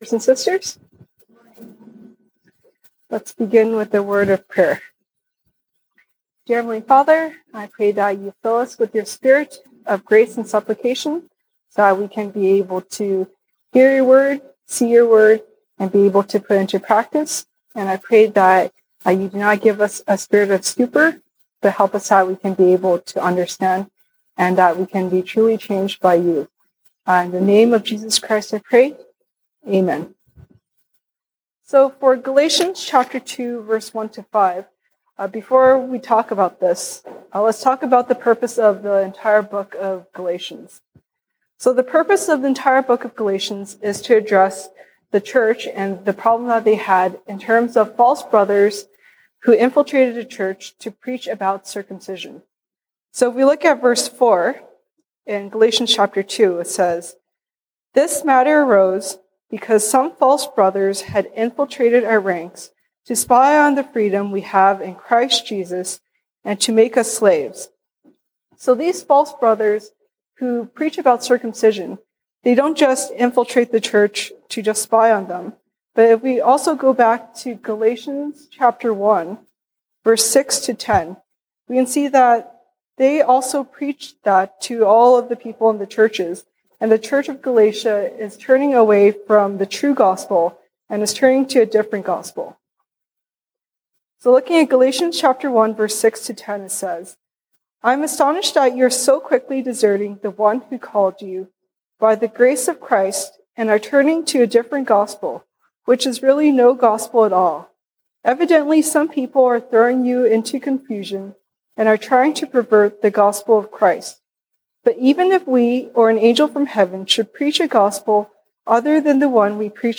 西堂證道 (英語) Sunday Service English: Circumcision vs The Cross